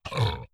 attack_enemy_default.wav